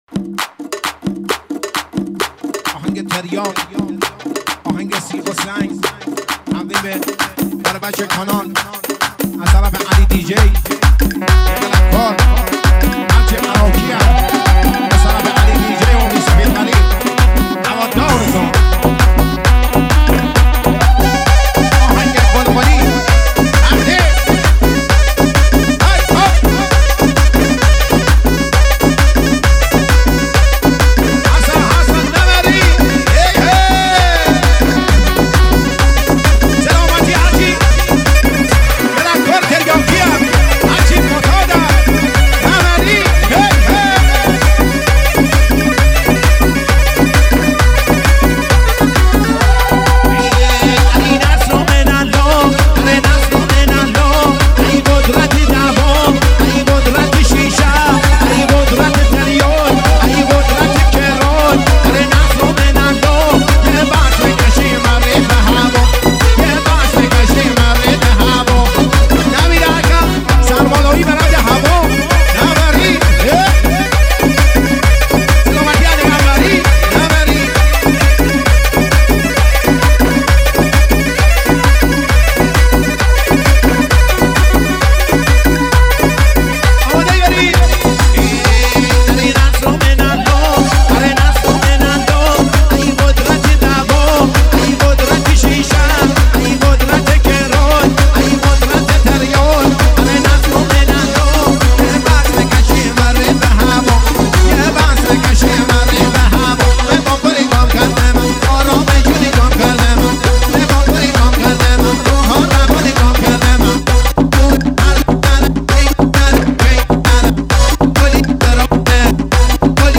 ورژن ریمیکس